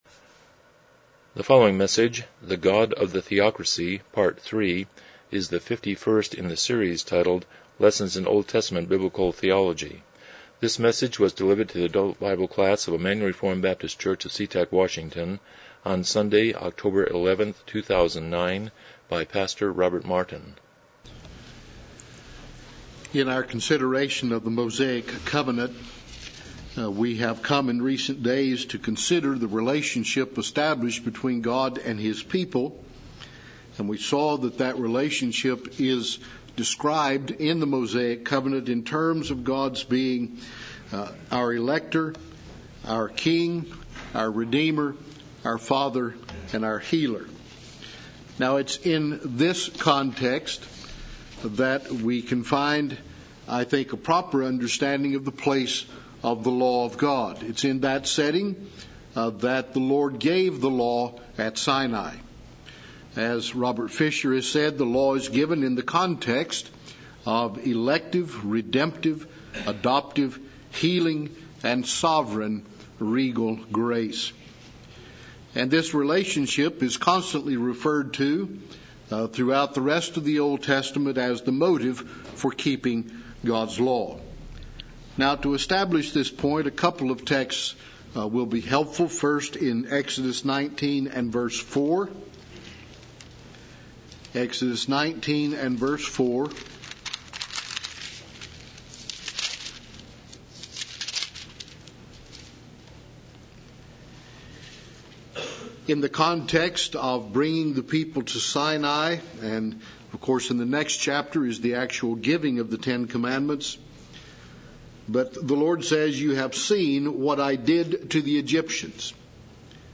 Lessons in OT Biblical Theology Service Type: Sunday School « How are you doing?